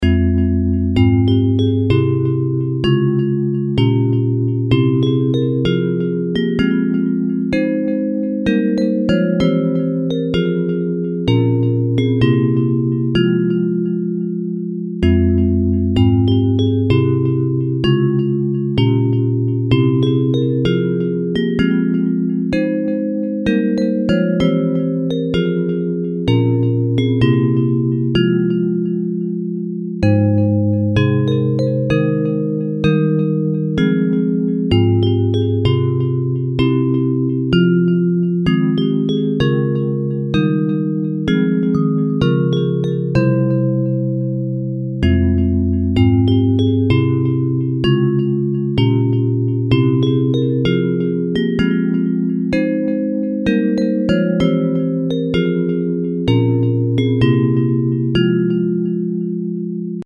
Hymns of praise
Bells Version (.mp3)